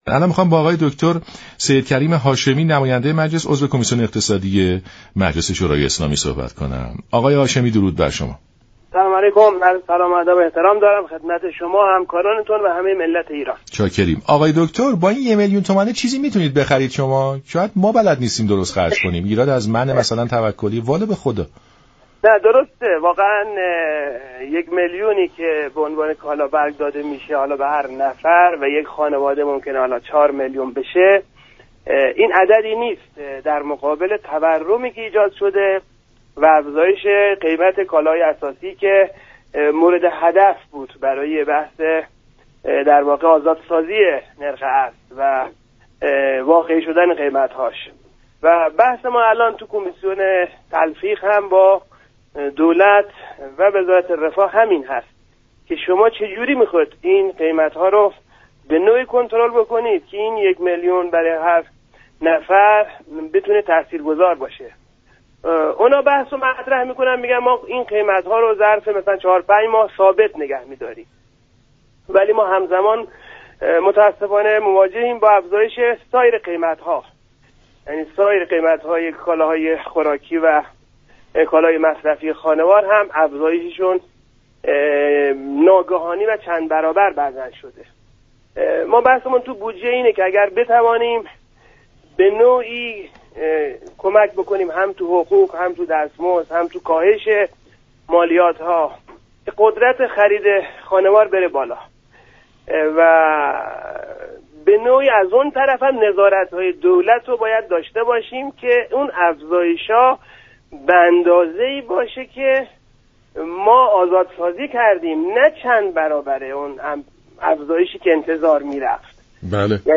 عضو كمیسیون‌اقتصادی‌مجلس در برنامه سلام‌صبح‌بخیر گفت: در بودجه سال 1405 اگر بتوانیم میزان حقوق و دستمزد‌ را افزایش و مقدار مالیات را كاهش دهیم این اتفاق در بالا‌بردن قدرت خرید مردم نقش مهمی را ایفا خواهد‌كرد.